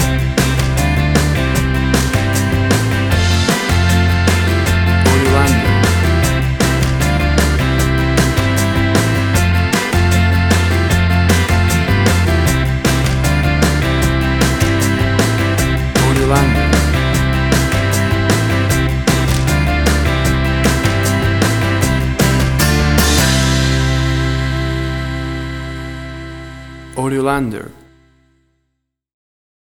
WAV Sample Rate: 16-Bit stereo, 44.1 kHz
Tempo (BPM): 77